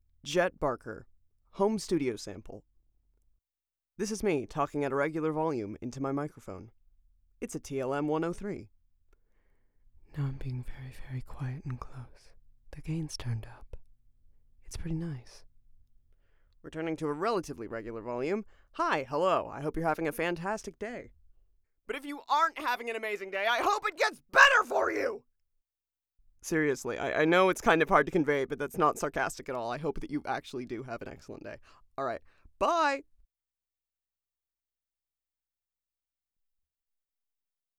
Studio Sample
(Raw audio)
Neumann TLM 103 | sE 2200a II